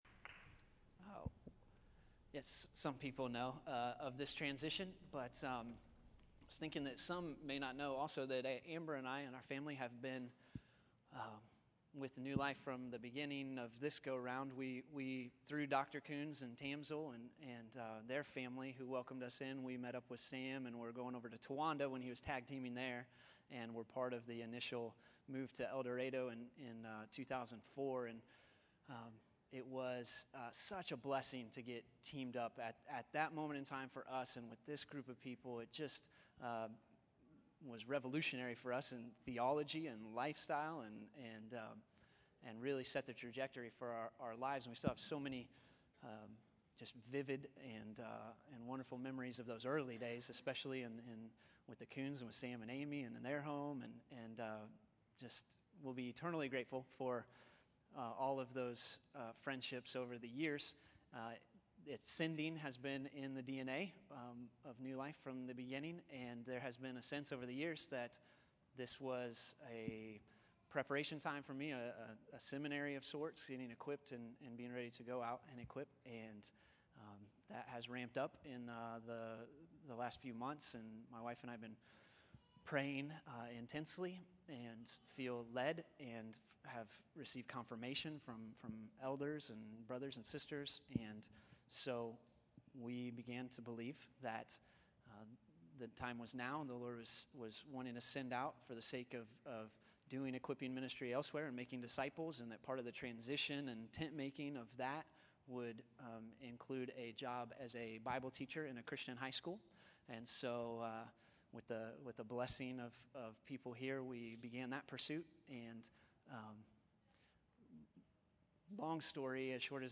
June 30, 2013      Category: Testimonies      |      Location: El Dorado